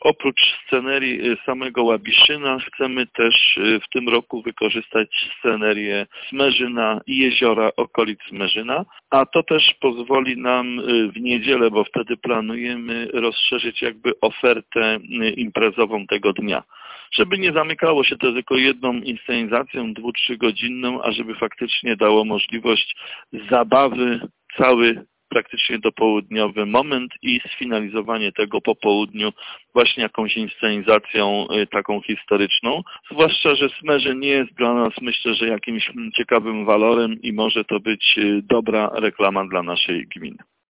Organizatorzy chcą, by jubileuszowa edycja była inne niż te, które odbywały się do tej pory, zdradza burmistrz Łabiszyna Jacek Idzi Kaczmarek.